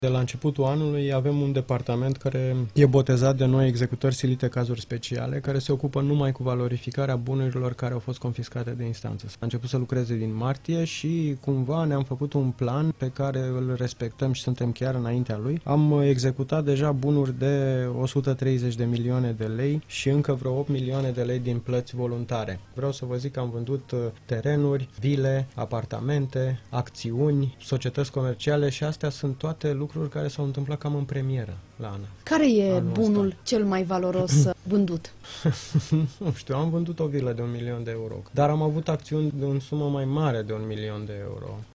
Multe dintre bunurile confiscate de la persoanele condamnate definitiv au fost deja valorificate. ANAF a obținut de exemplu 1 milion de lei după ce a vândut o vilă confiscată – a spus șeful instituției, Dragoș Doroș, la Interviurile Europa FM.